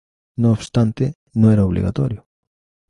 o‧bli‧ga‧to‧rio
/obliɡaˈtoɾjo/